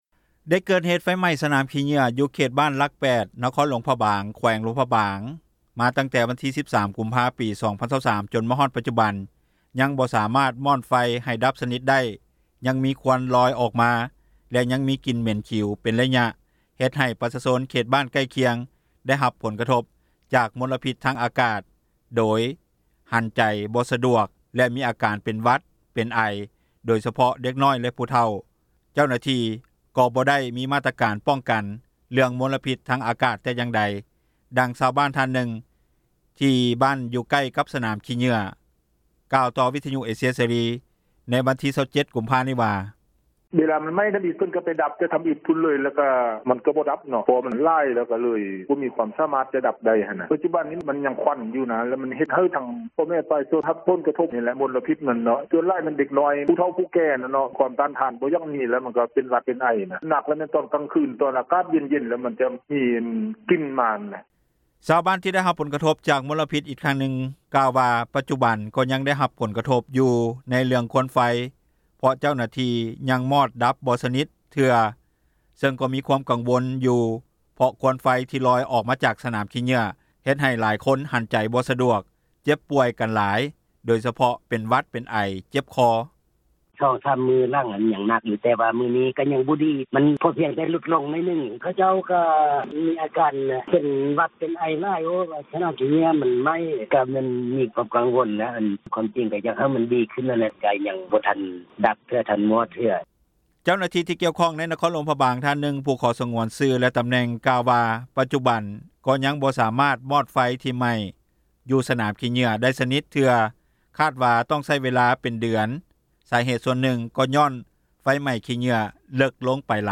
ດັ່ງຊາວບ້ານທ່ານນຶ່ງ ທີ່ບ້ານຢູ່ໃກ້ກັບ ສນາມຂີ້ເຫຍື້ອ ກ່າວຕໍ່ວິທຍຸ ເອເຊັຽເສຣີ ໃນວັນທີ 27 ກຸມພາ ນີ້ວ່າ:
ດັ່ງຊາວບ້້ານ ໃນໂຕເມືອງນະຄອນຫລວງພຣະບາງ ກ່າວວ່າ: